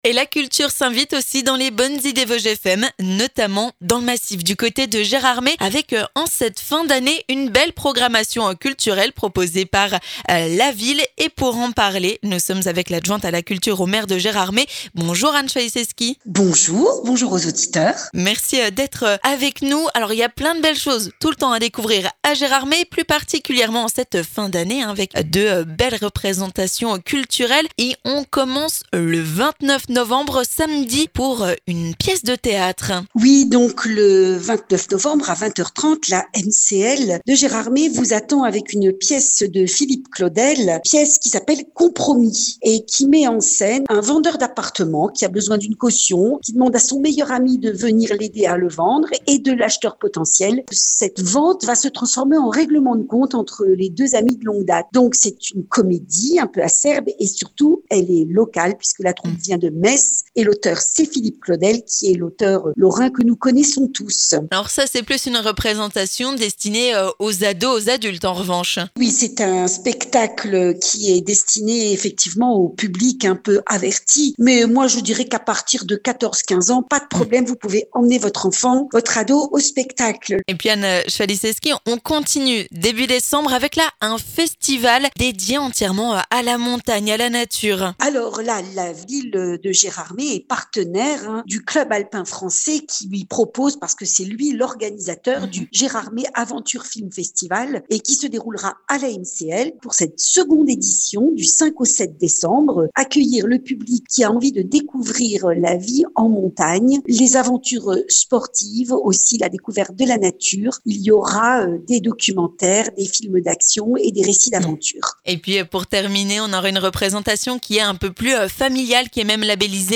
Direction Gérardmer pour une nouvelle programmation culturelle qui s'annonce éclectique : la municipalité vous propose du théâtre mais aussi des spectacles destinés aux famille. Sans oublier un festival du film de montagne... on vous dit tout dans les Bonnes Idées Vosges FM avec Anne Chwaliszewski, adjointe à la culture à la mairie de Gérardmer.